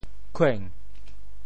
潮州 kuêng2 文 对应普通话: qǐng 通“顷”，详见“顷”字义。
khueng2.mp3